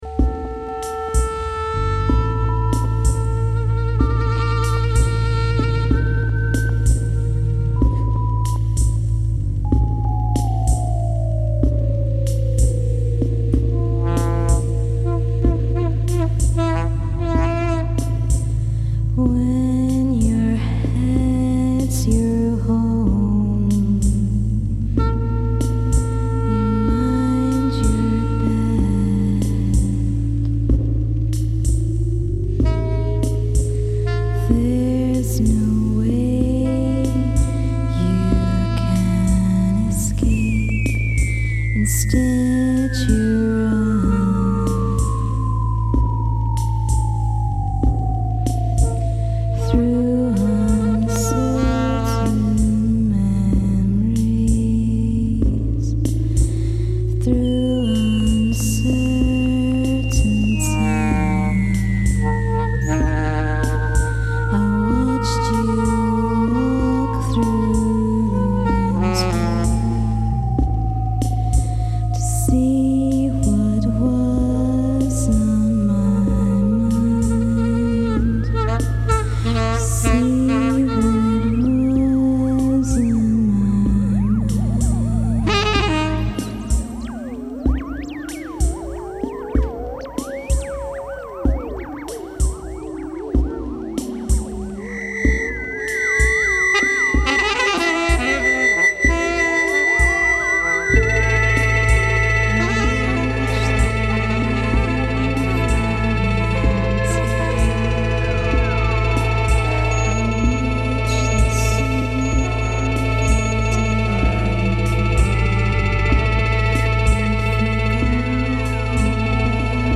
Excellent various ambient compilation.